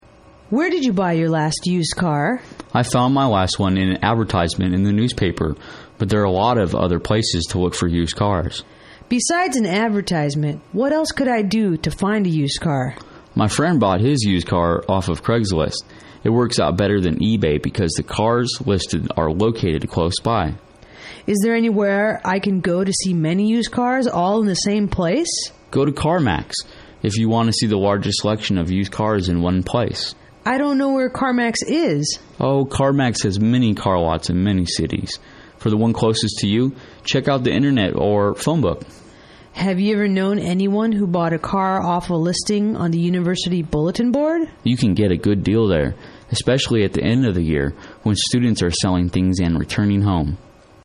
英语情景对话-Where to Buy a Used Car(3) 听力文件下载—在线英语听力室